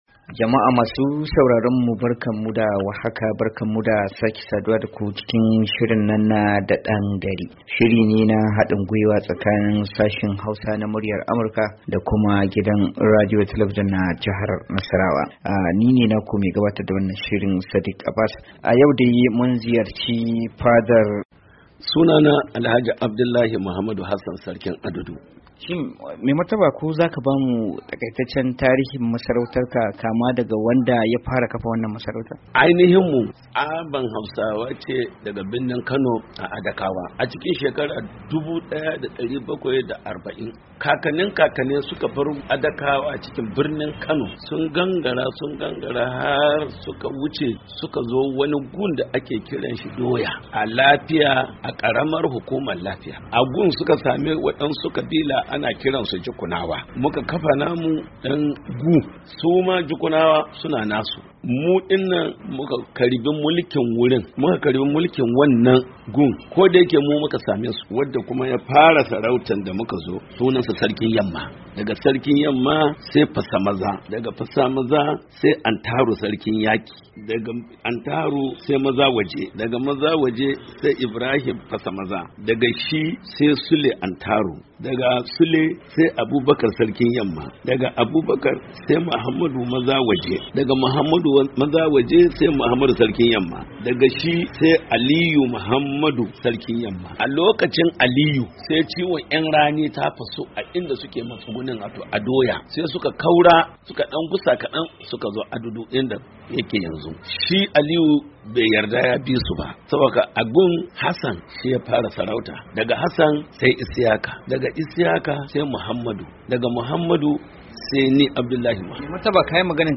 Gidan Rediyo da Talabijin na jihar Nasarawa ya yi hira da mai Martaba Sarkin Adudu